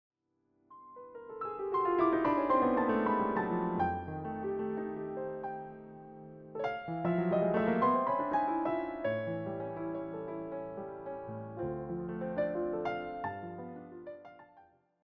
all rendered as solo piano pieces.
intimate, late-night atmosphere